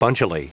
Prononciation du mot bunchily en anglais (fichier audio)
Prononciation du mot : bunchily